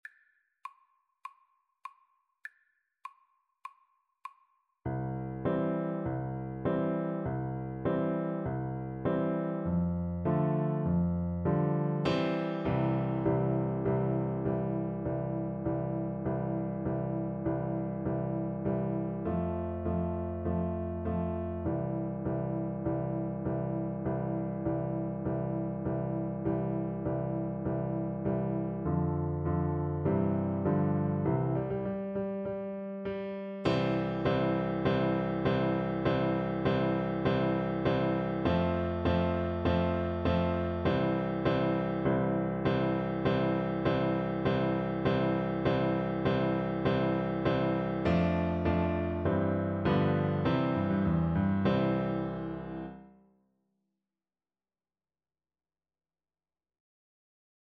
March
4/4 (View more 4/4 Music)
Piano Duet  (View more Intermediate Piano Duet Music)